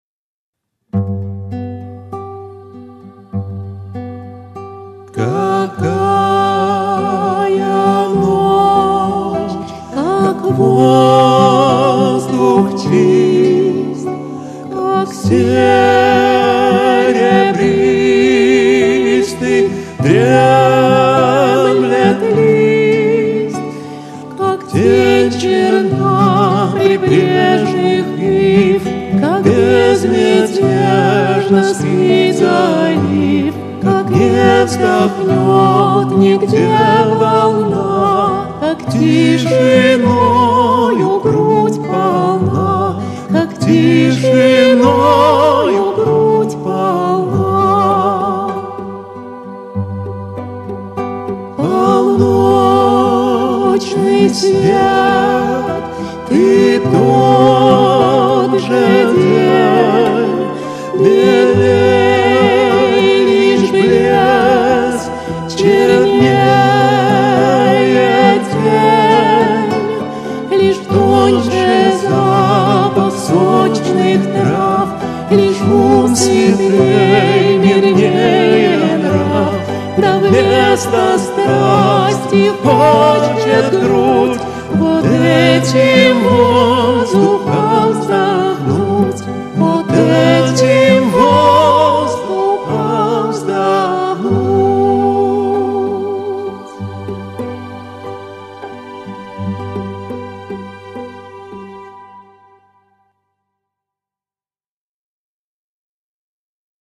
поющий в жанре лирической песни